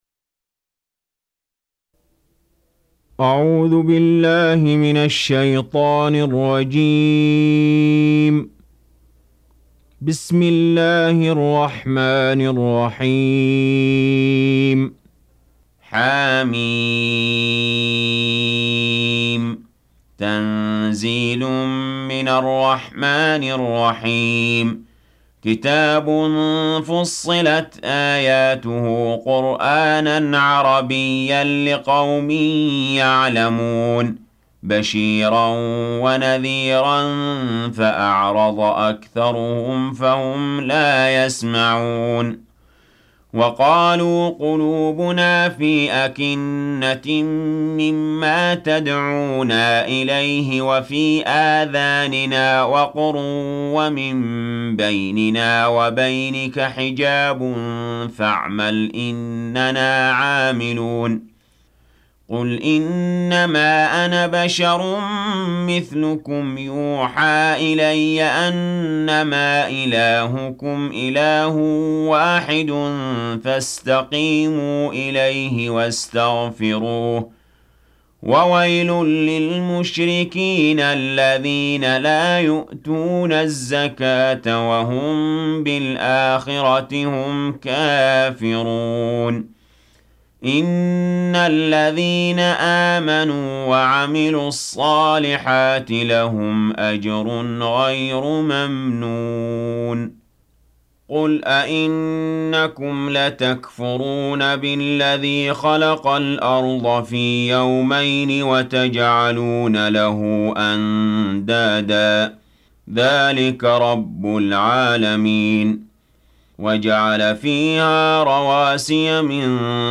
41. Surah Fussilat سورة فصّلت Audio Quran Tarteel Recitation
حفص عن عاصم Hafs for Assem
Surah Sequence تتابع السورة Download Surah حمّل السورة Reciting Murattalah Audio for 41. Surah Fussilat سورة فصّلت N.B *Surah Includes Al-Basmalah Reciters Sequents تتابع التلاوات Reciters Repeats تكرار التلاوات